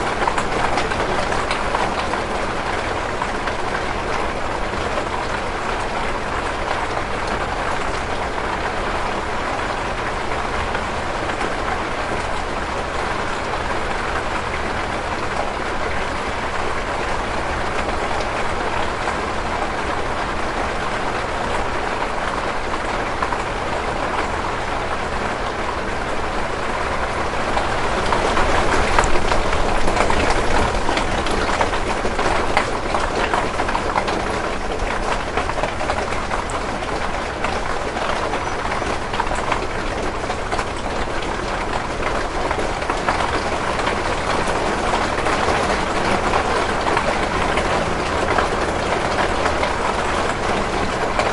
hail.ogg